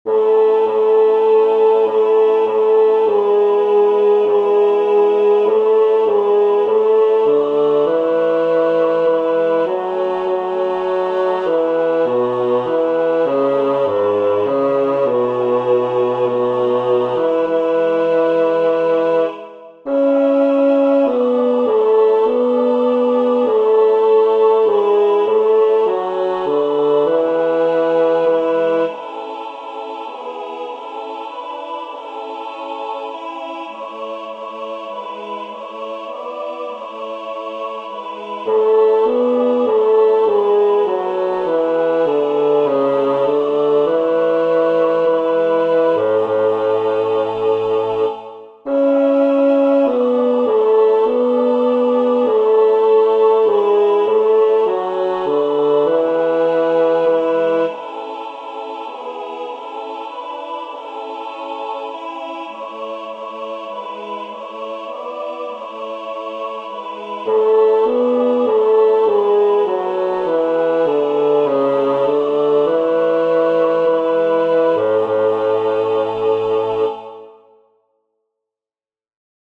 El tempo está marcado como negra=100, aunque suele depender mucho de quién la dirija y del carácter que le quiera dar, si hay orquesta acompañando, solistas..
Para aprender la melodía os dejo estos MIDIS de fabricación casera, con la voz principal destacada por encima del resto.
adeste-fideles-midi-bajo.mp3